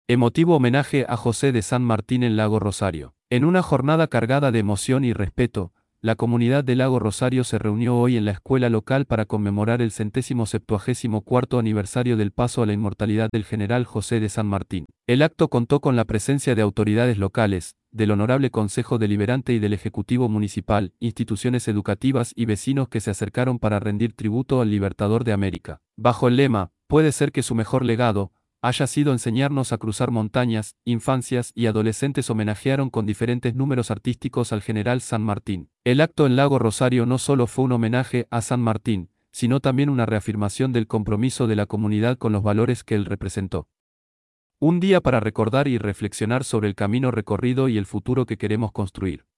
acto_san_martin_lago_rosario.mp3